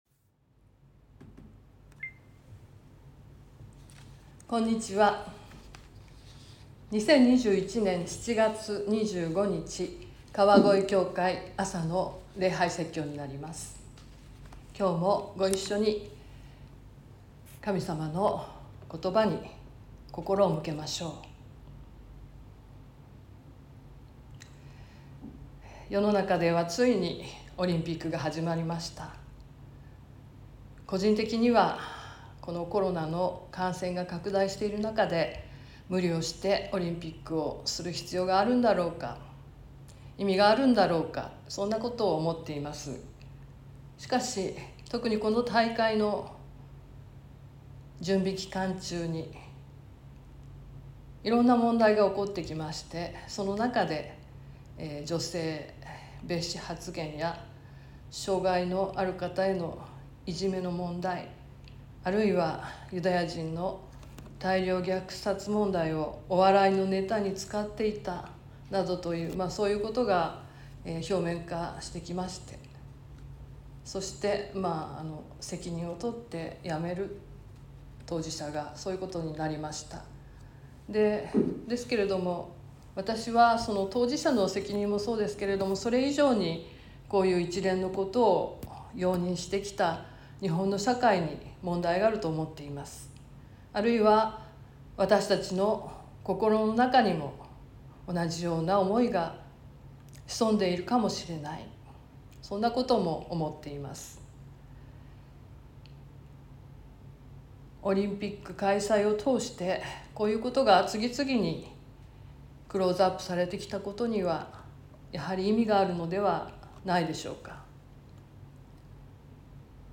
川越教会。説教アーカイブ。
音声ファイル 礼拝説教を録音した音声ファイルを公開しています。